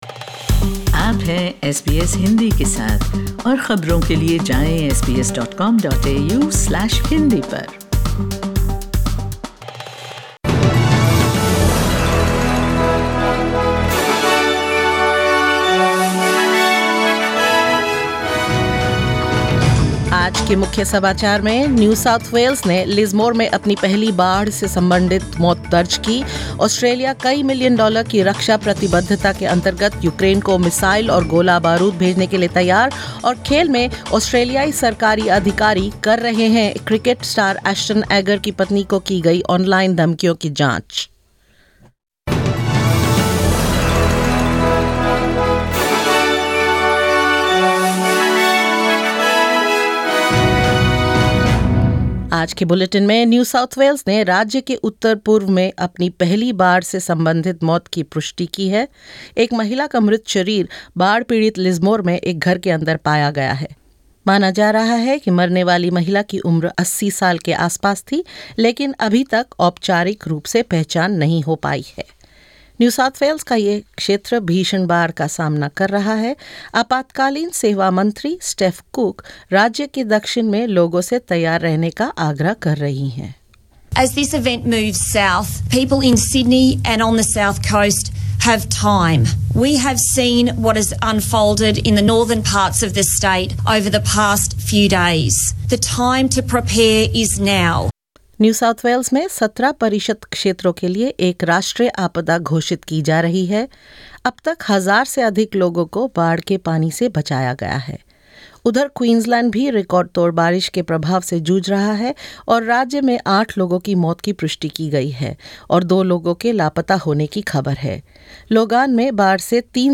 SBS Hindi News 01 March 2022: New South Wales flood crisis continues as first fatality confirmed
In this latest SBS Hindi bulletin: New South Wales reports its first confirmed flood-related death in Lismore; Australia promises missiles and ammunition to Ukraine as part of a multi-million-dollar defence commitment; In sports, government officials investigate online threats made to the wife of Australian cricketer Ashton Agar and more.